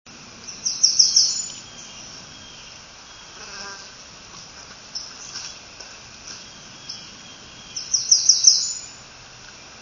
Wood Warbler
Robbins:  "Song is very high and thin, often with an exceedingly high-pitched ending, 4-6/min."
warbler_blackburnian_765.wav